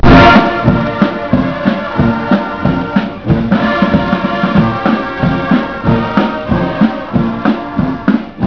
A parade in Oslo